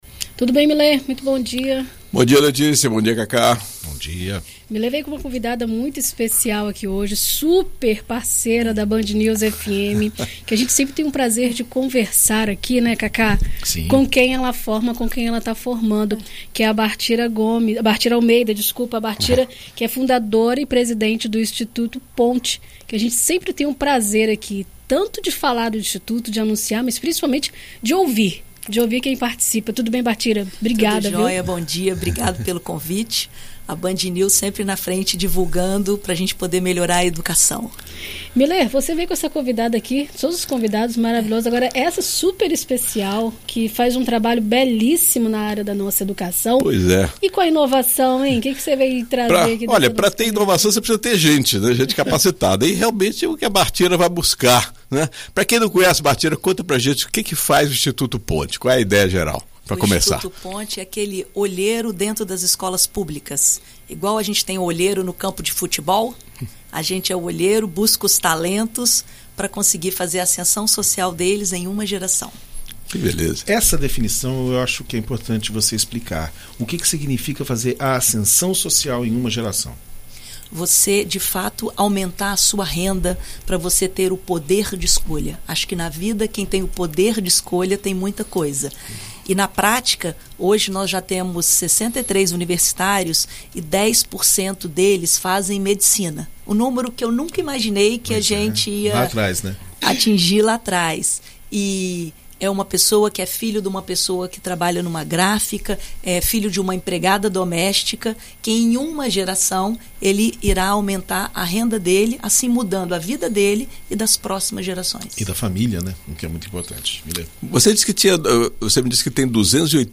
recebe no estúdio